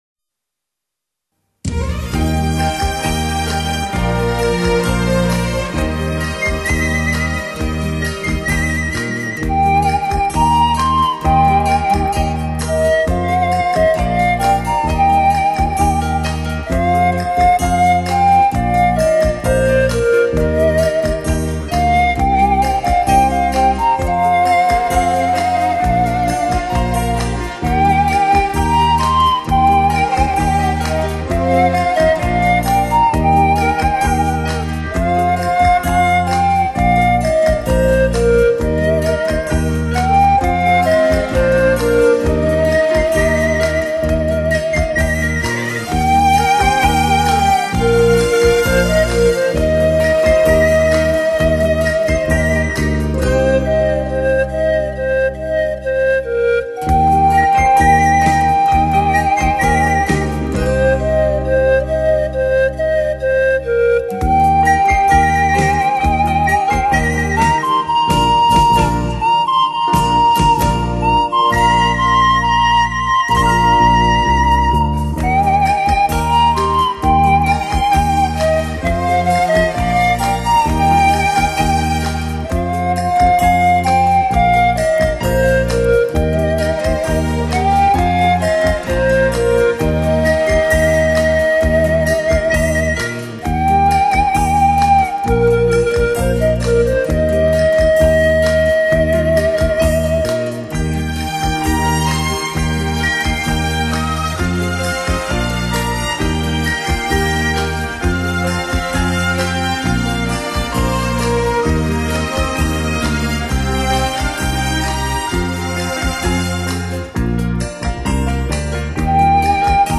纯音乐
悠扬如诗的清新乐曲
仿似天籁之声